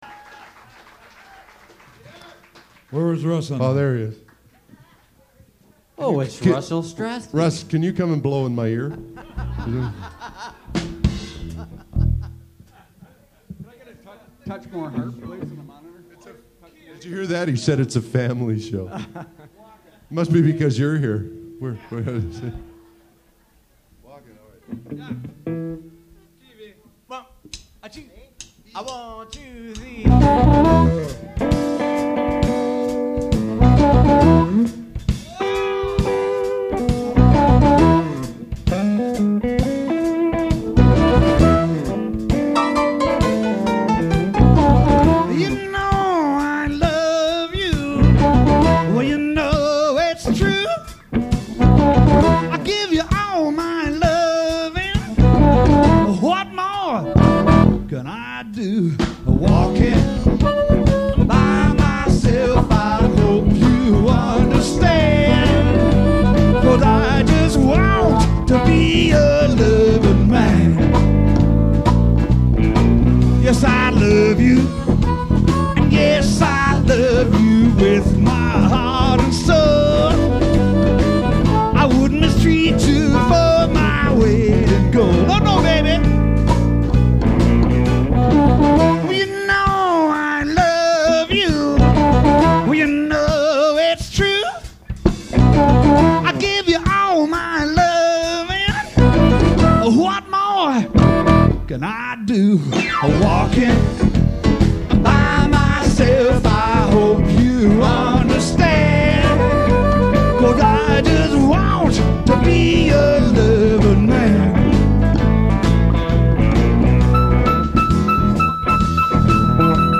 Birthday Party
roc'n'funk'n'soul'n'blues
Guitar / lead vocals
Bass / vocals
Drums / vocals
Piano
Harp
Sax